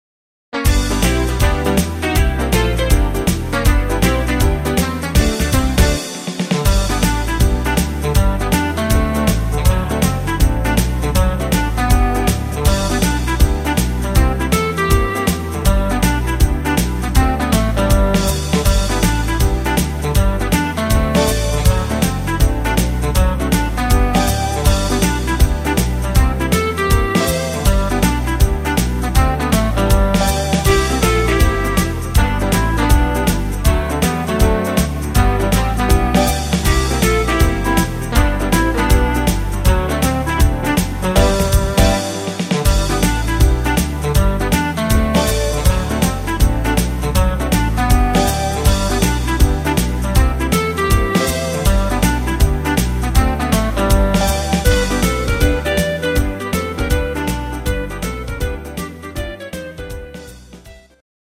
instr Saxophon